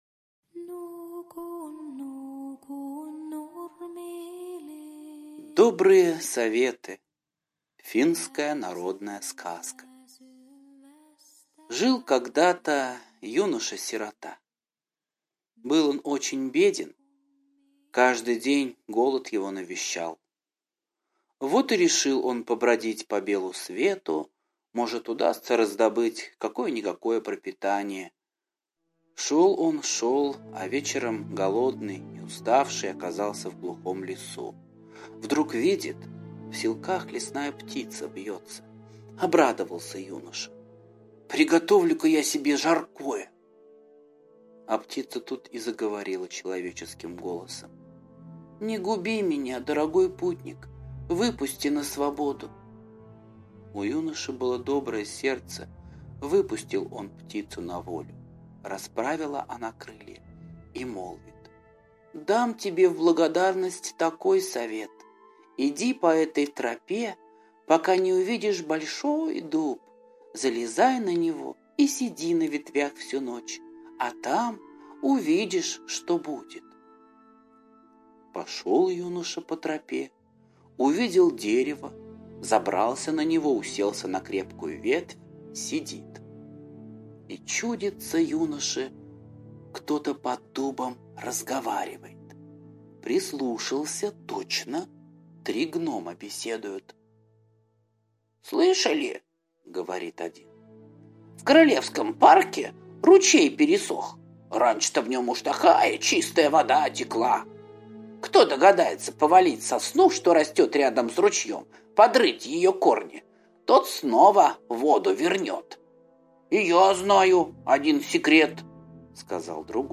Добрые советы - финская аудиосказка - слушать онлайн